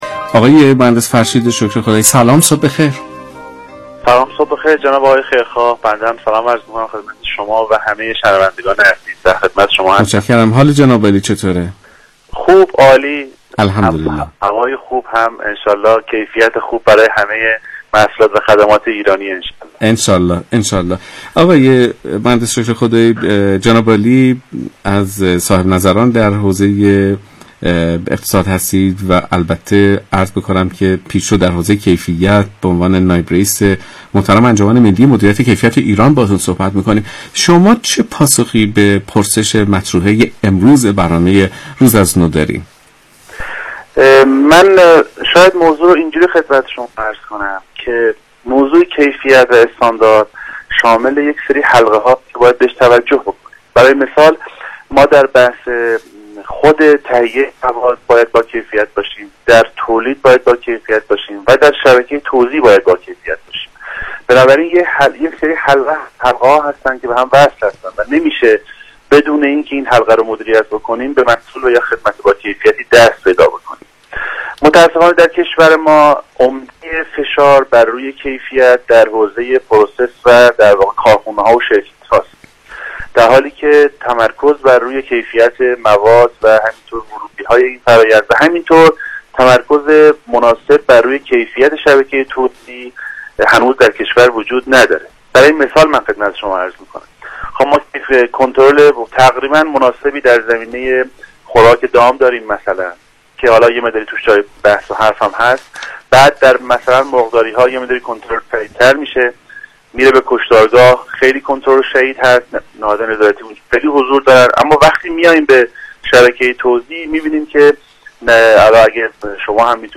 مصاحبه با رادیو اقتصاد